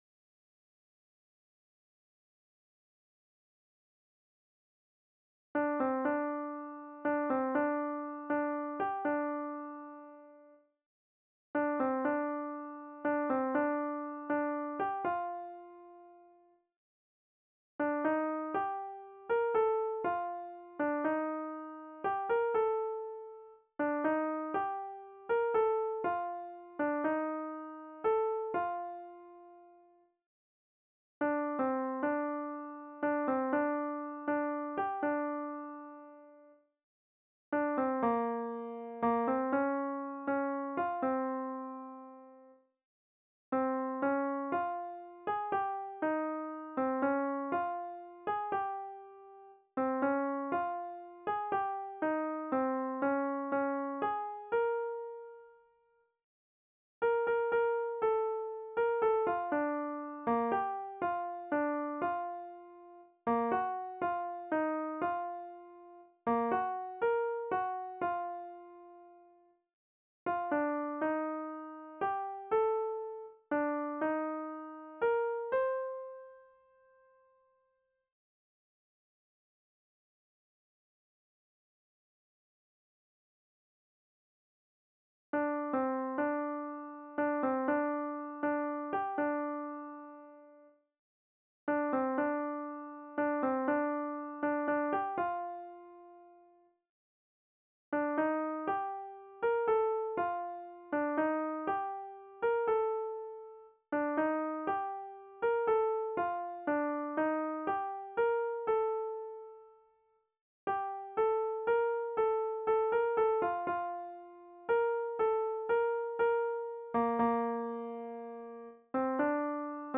- Partie chóralne